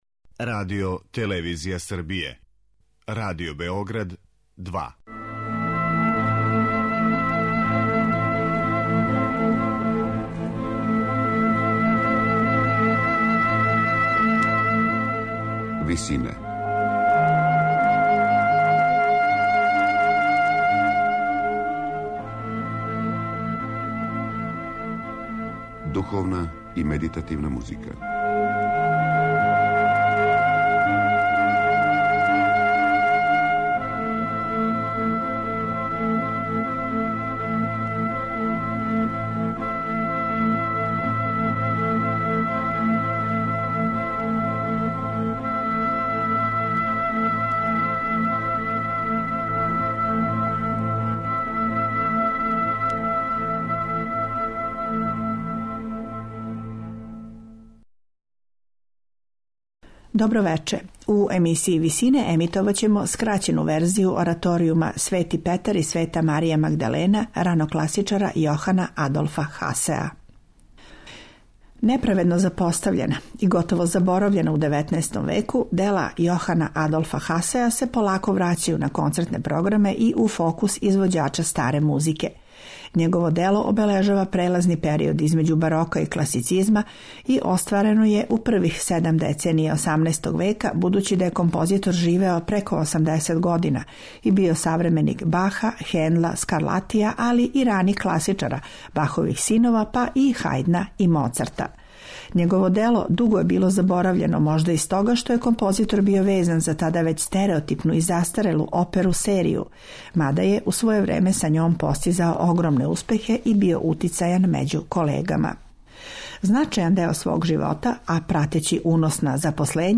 Ораторијум 'Свети Петар и Света Марија Магдалена', Јохана Адолфа Хасеа